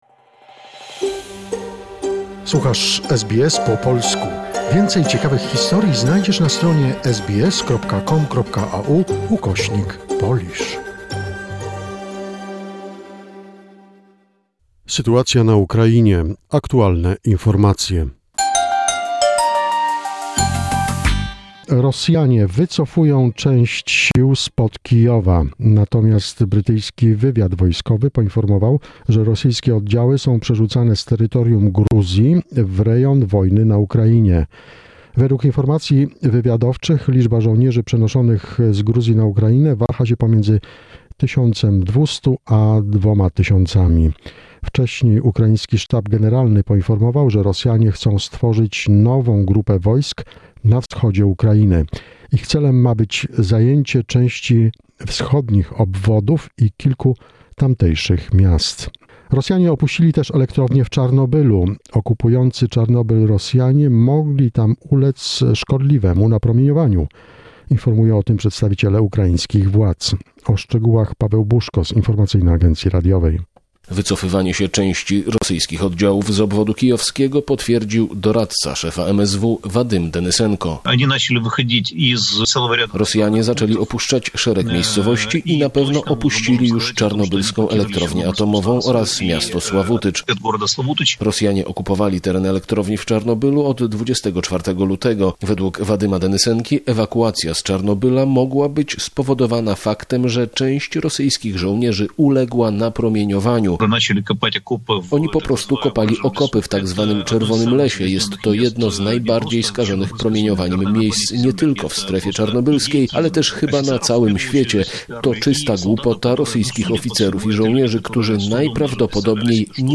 The most recent information about the situation in Ukraine, a short report prepared by SBS Polish.